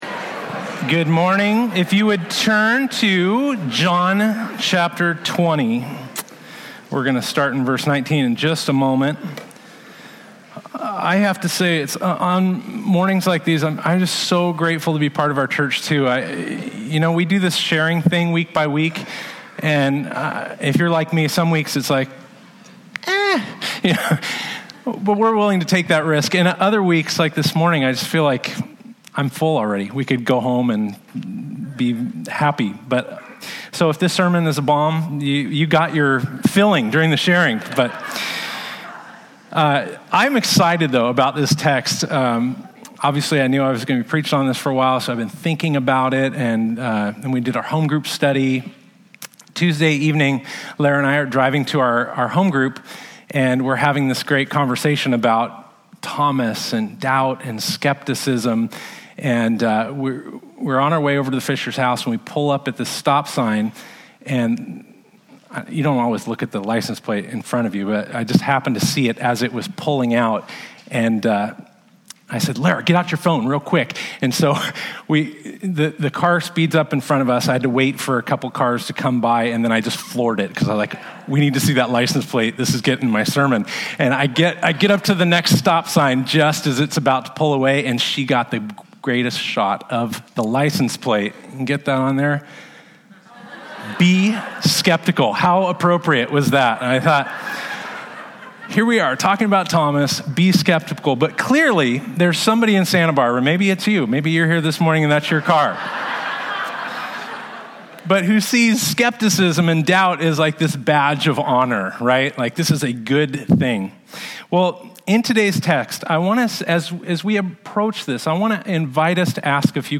19-31 Service Type: Sunday Topics